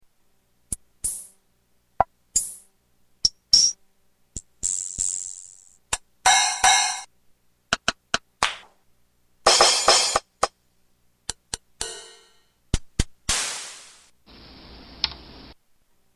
Category: Sound FX   Right: Personal